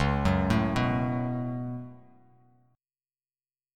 C#7sus4#5 chord